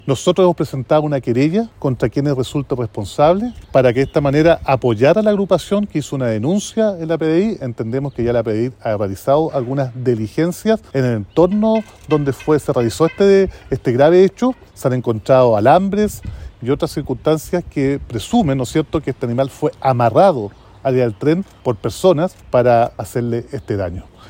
El alcalde de Temuco Roberto Neira informó que la PDI ya realizó diligencias en el lugar, encontrando alambres y otros indicios que refuerzan la hipótesis de participación de terceros en el ataque.